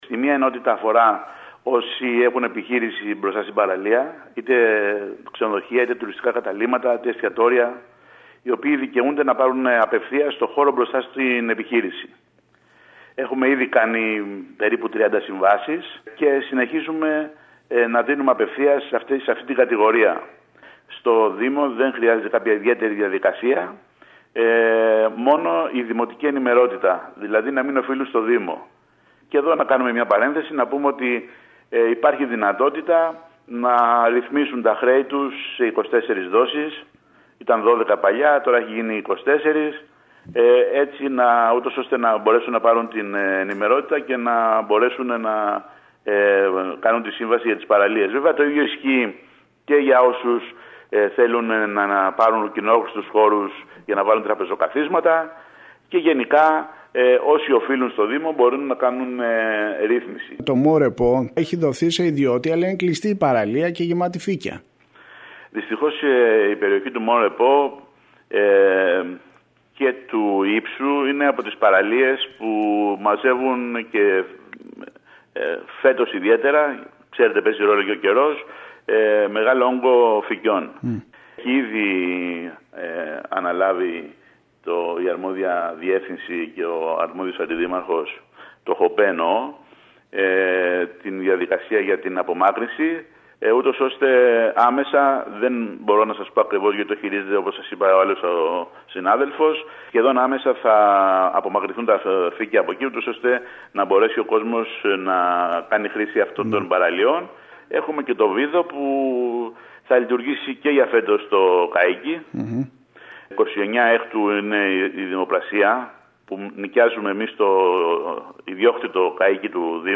Ο Αντιδήμαρχος Οικονομικών Υπηρεσιών Γιώργος Παντελιός, μιλώντας στην ΕΡΤ Κέρκυρας αναφέρθηκε στην εικόνα που παρουσιάζει η πλαζ του Μον Ρεπό, τονίζοντας ότι τις επόμενες ημέρες ο Δήμος θα καθαρίσει την παραλία από τα φύκια, επιτρέποντας την είσοδο στους λουόμενους.